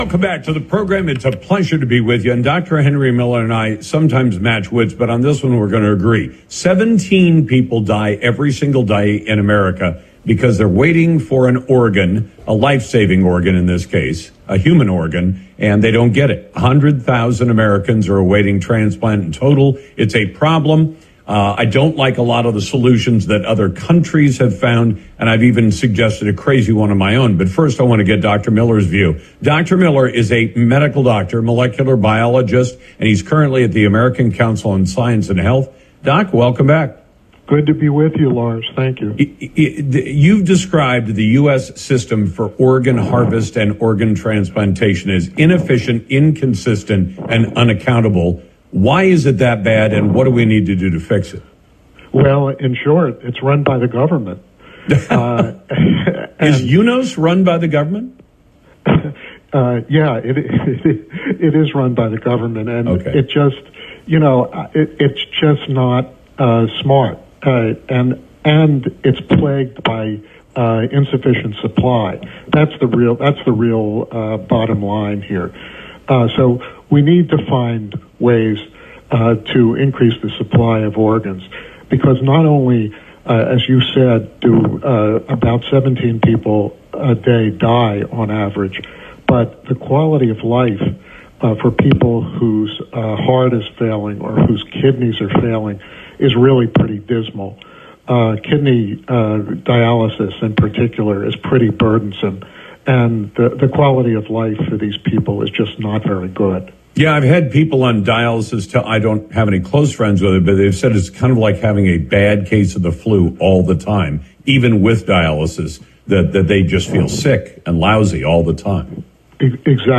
Lars Larson and I discussed the urgent issue of organ transplants.
You can find our entire conversation